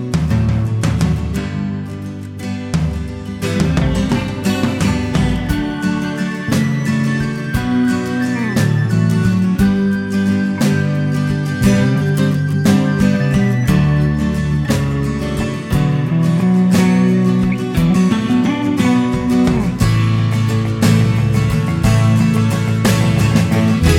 With Harmony Pop (1960s) 2:18 Buy £1.50